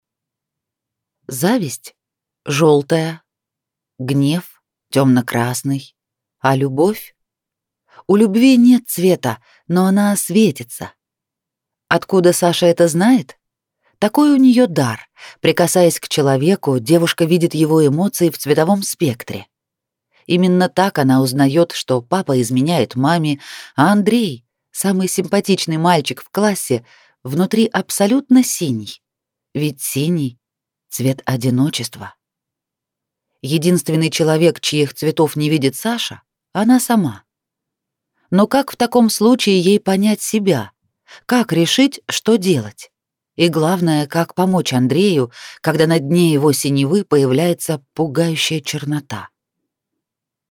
Аудиокнига Самый синий из всех | Библиотека аудиокниг
Прослушать и бесплатно скачать фрагмент аудиокниги